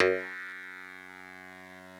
genesis_bass_030.wav